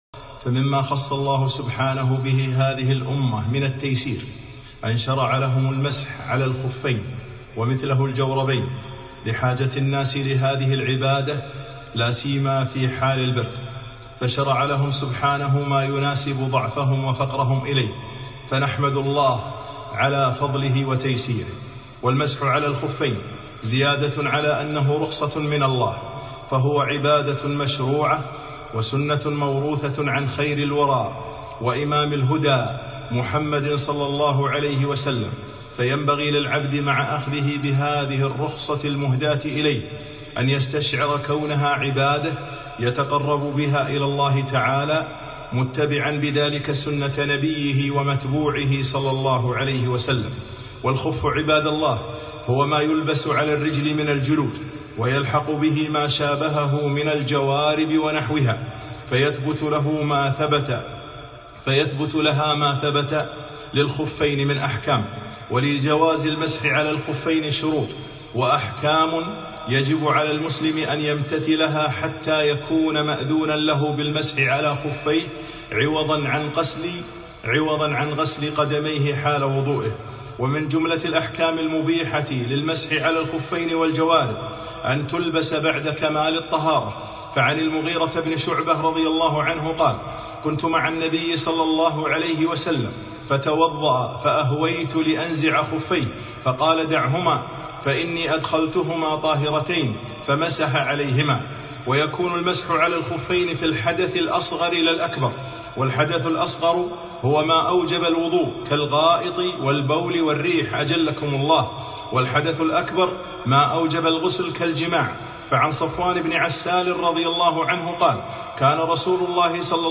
خطبة - المسح على الخفين